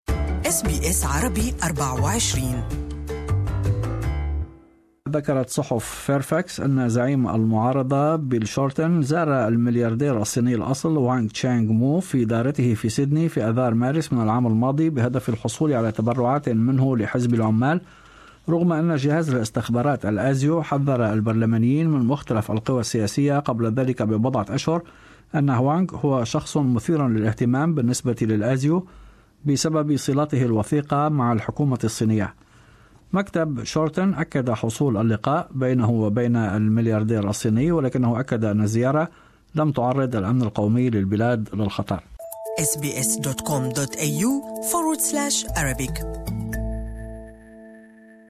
Speaking in parliament, Malcolm Turnbull has questioned Mr Shorten's integrity.